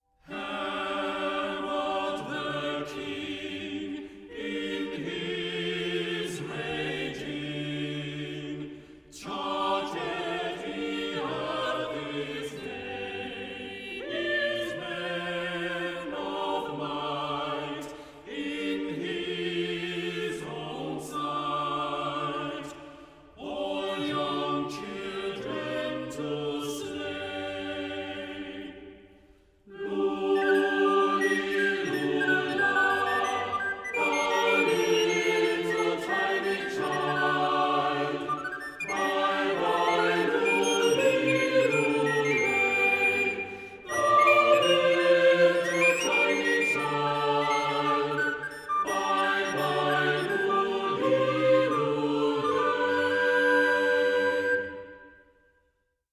traditional English, fifteenth century
recorder
for recorder and choir